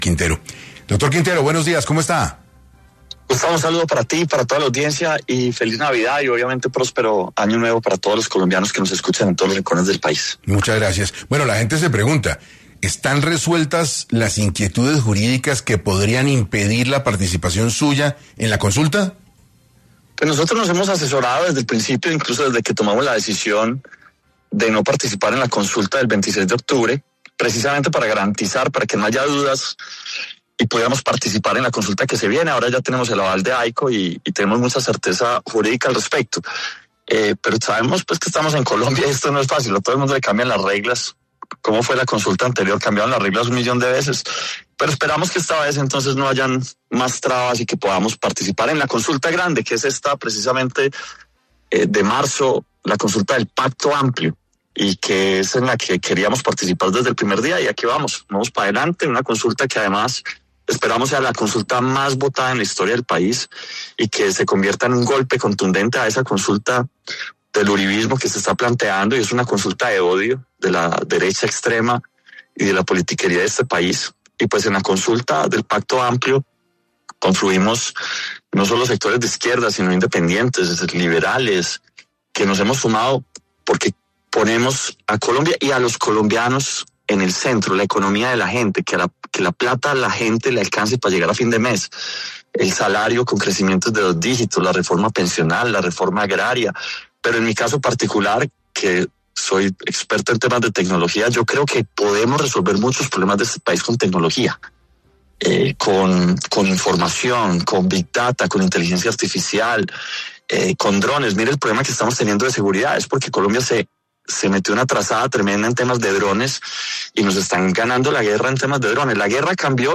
En entrevista para 6AM, el precandidato presidencial Daniel Quintero se refirió a las razones por las cuales participará de la consulta interpartidista por el partido Frente Amplio tras el anuncio que realizó en las últimas horas, además del decreto de emergencia económica emitido recientemente por el Gobierno.